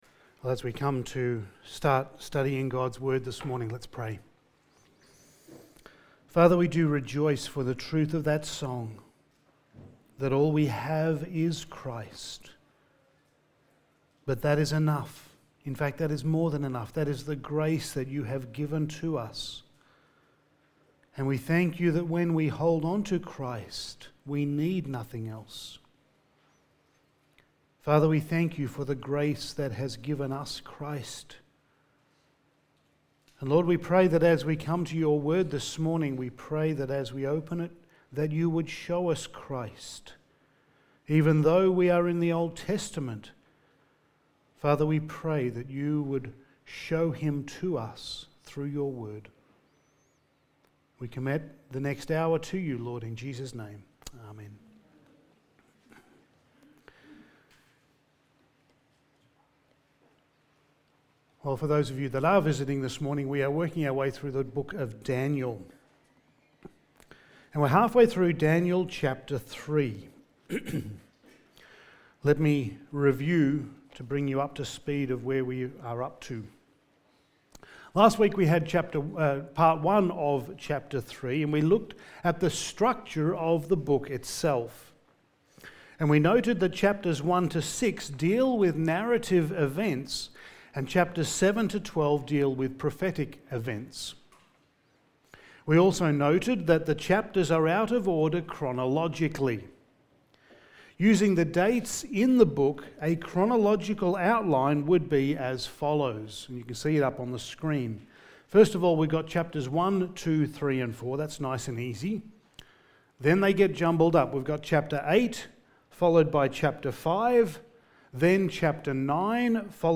Passage: Daniel 3:19-30 Service Type: Sunday Morning